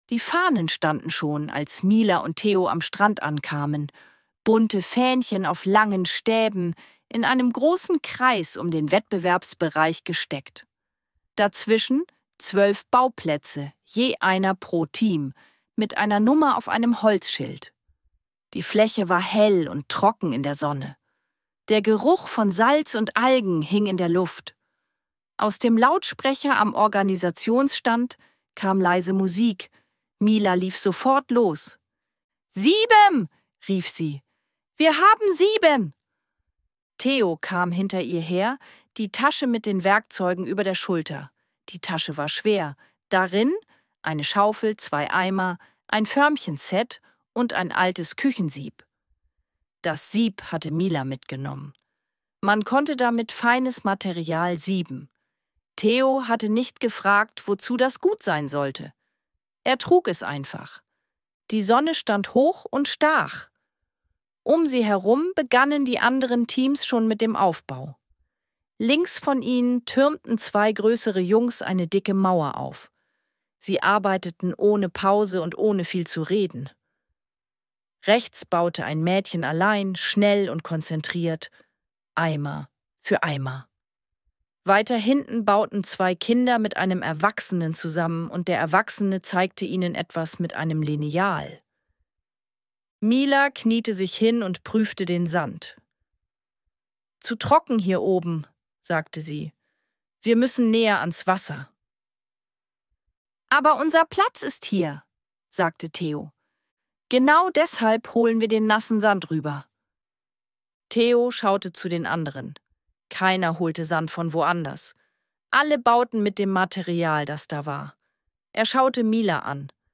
Ruhige Kindergeschichten zum Anhören